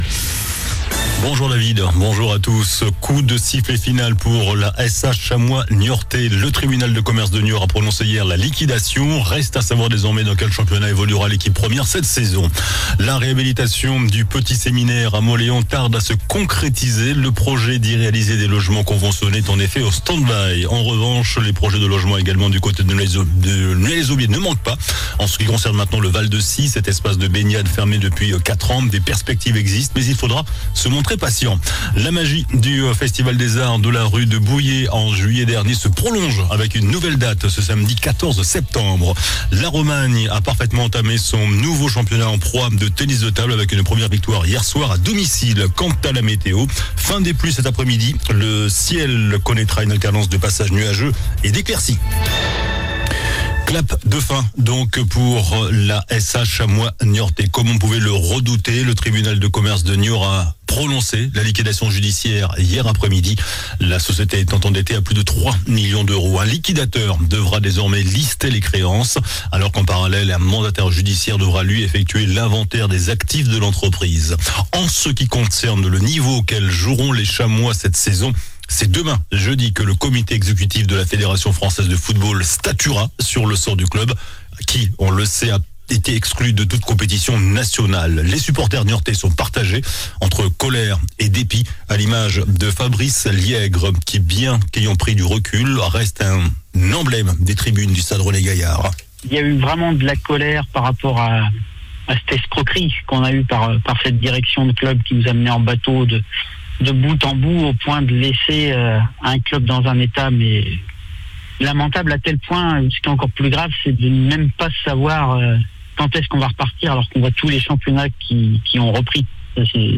JOURNAL DU MERCREDI 11 SEPTEMBRE ( MIDI )